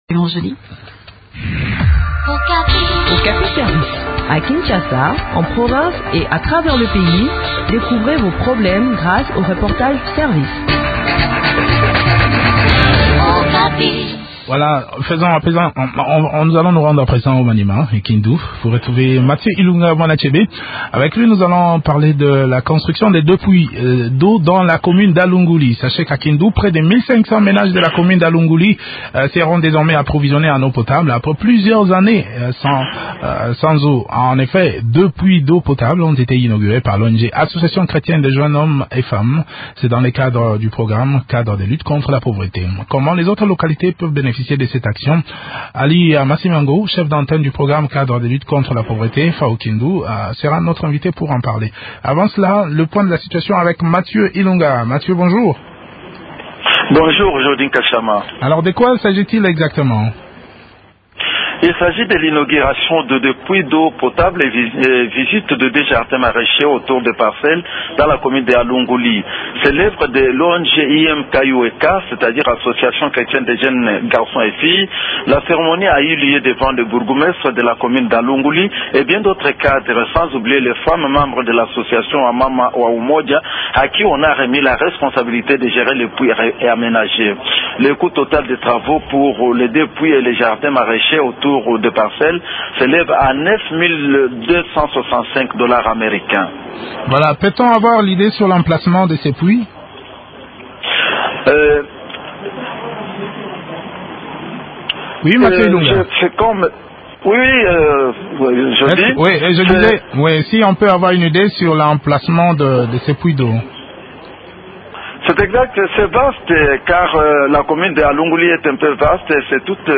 s’entretient sur le sujet